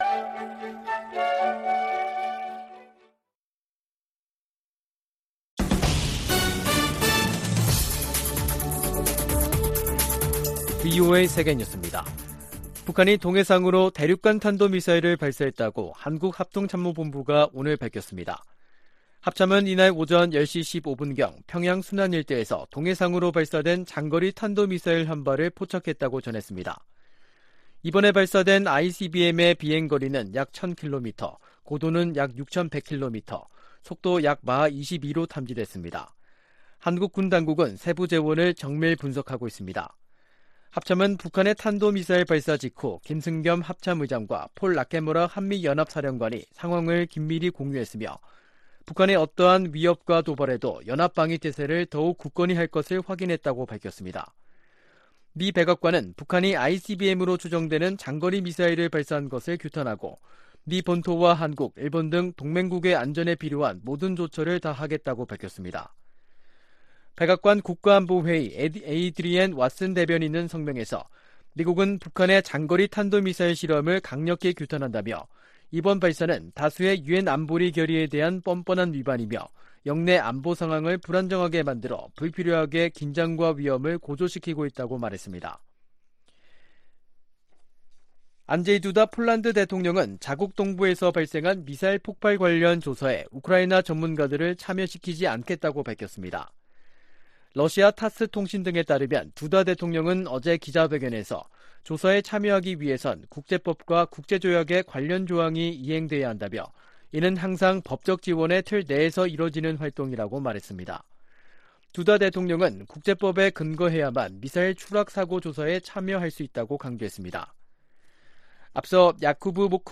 VOA 한국어 간판 뉴스 프로그램 '뉴스 투데이', 2022년 11월 18일 2부 방송입니다. 북한이 오늘, 18일, 신형 대륙간탄도미사일, ICBM인 ‘화성-17형’을 시험발사해 정상비행에 성공한 것으로 알려졌습니다. 한국 합동참모본부는 이에 대응해 F-35A 스텔스 전투기를 동원해 북한 이동식발사대(TEL) 모의표적을 타격하는 훈련을 실시했습니다.